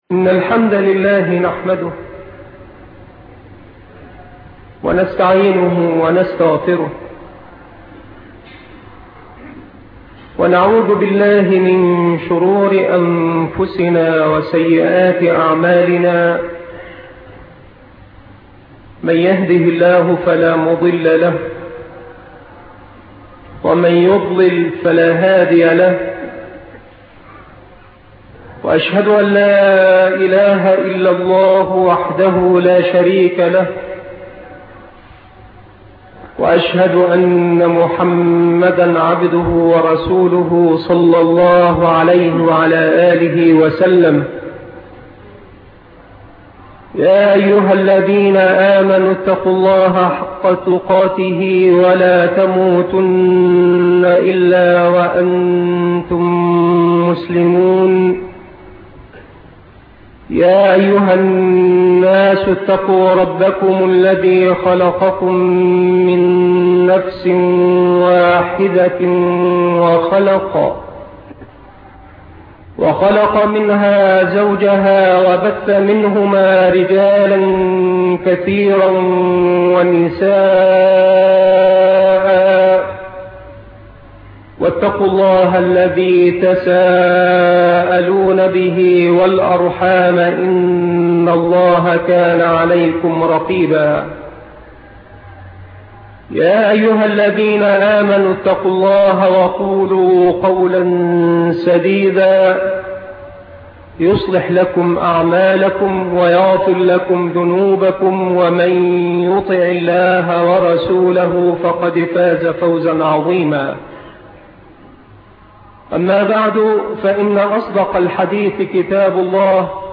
محاضرة شرف حَمَلَة القرآن 2 الشيخ محمد بن سعيد رسلان